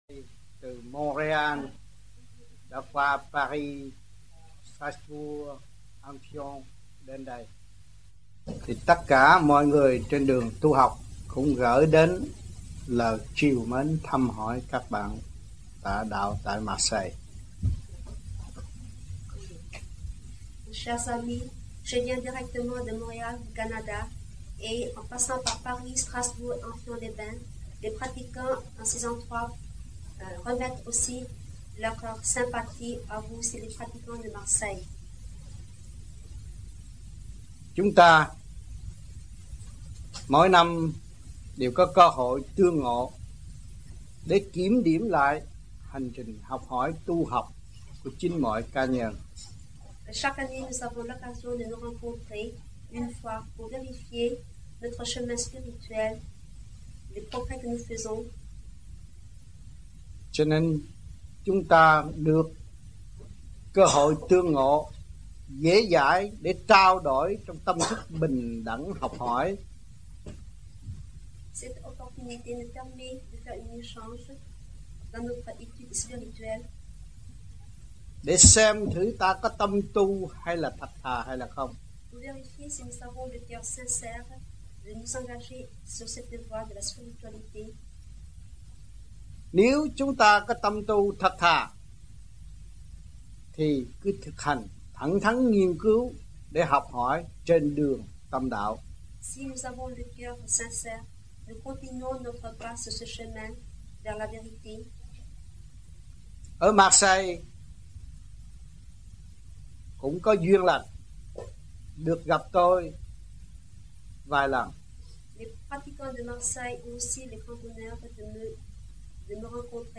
1982-12-11 - MARSEILLE - THUYẾT PHÁP 01